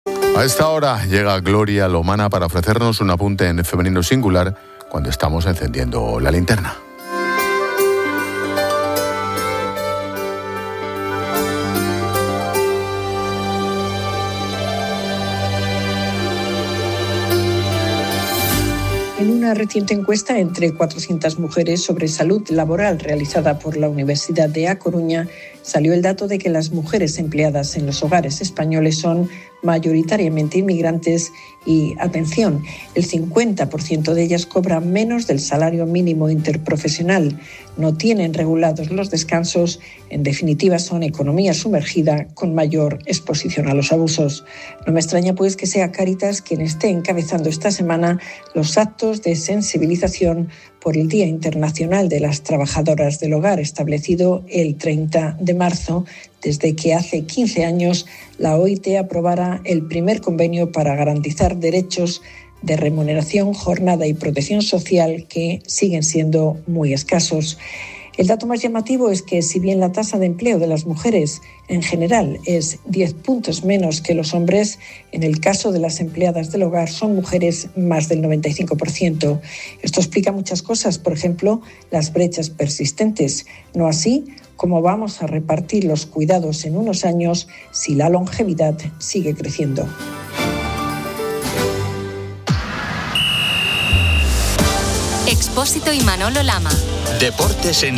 Gloria Lomana hace su apunte en Femenino Singular en La Linterna sobre la precariedad de las trabajadoras del hogar